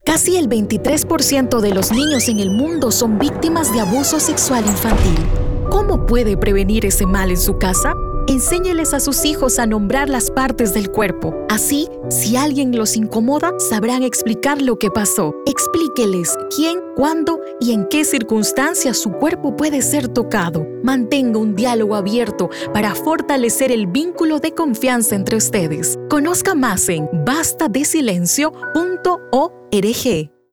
spot-radio-bs24.wav